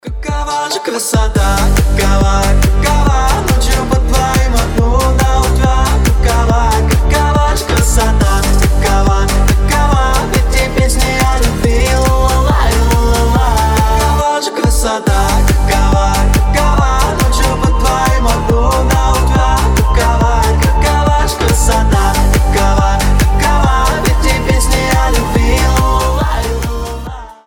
• Качество: 320, Stereo
позитивные
веселые
заводные